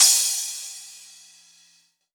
• Big Crash F Key 07.wav
Royality free crash cymbal sound tuned to the F note. Loudest frequency: 6778Hz
big-crash-f-key-07-We9.wav